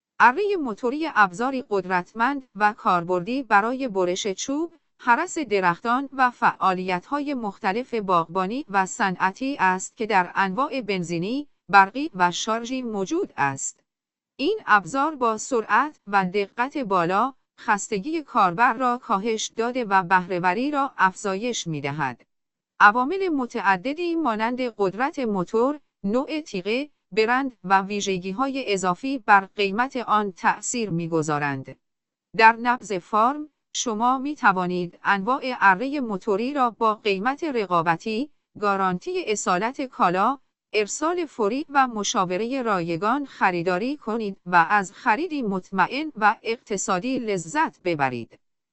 اره موتوری
chainsaw.ogg